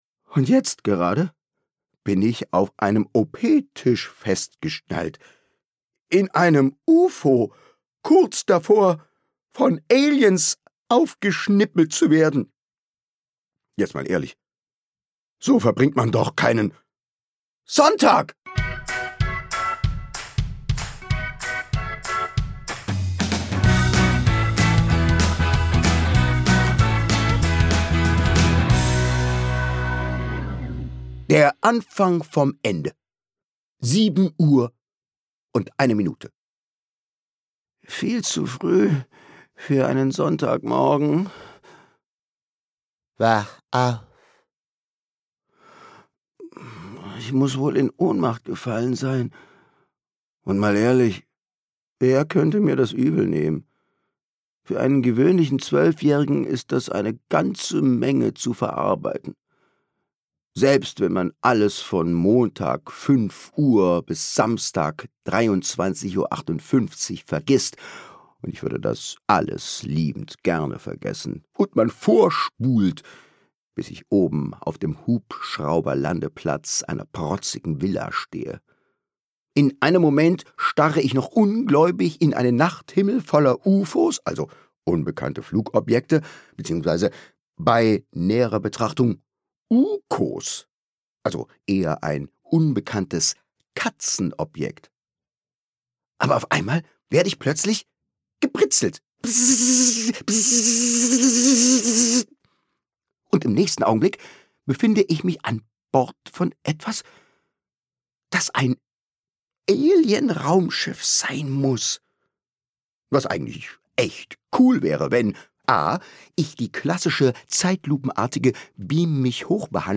Schule, Peinlichkeiten und ganz viel Humor – Cooles Hörbuch für Kinder ab 10 Jahre